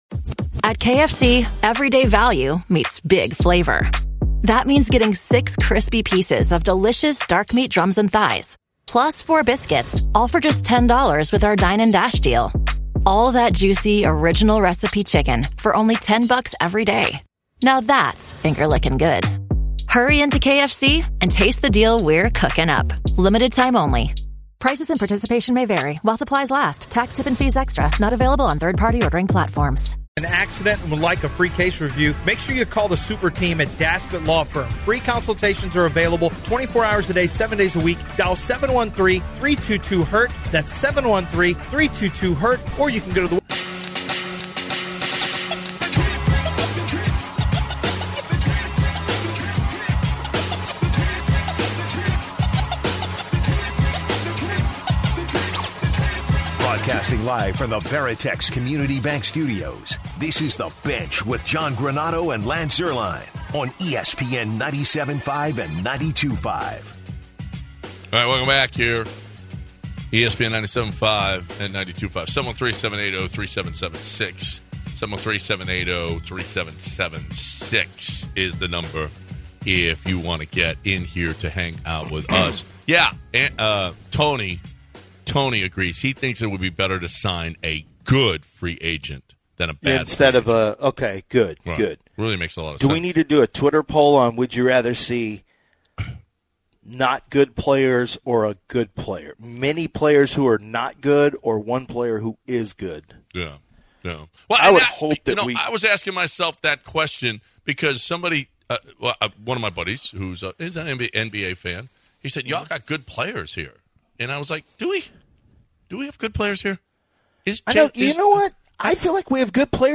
different guest spoke out about their opinion on the draft.